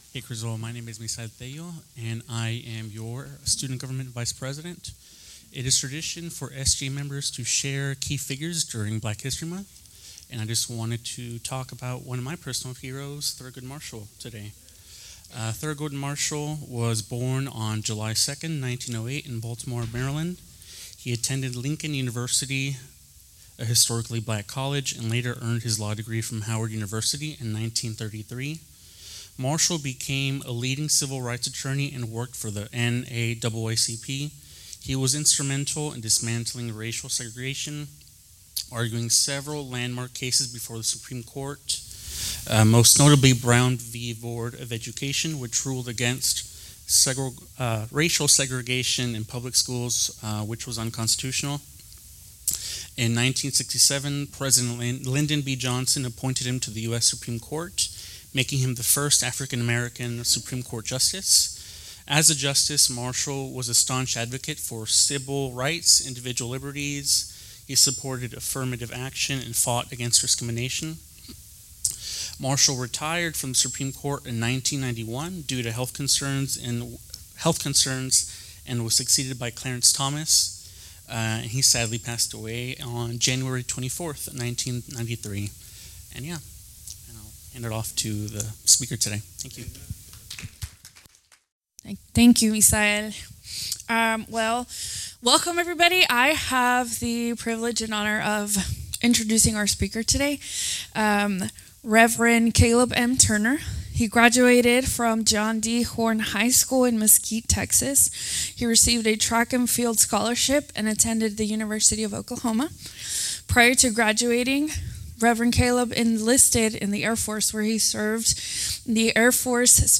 Criswell College Chapel Service.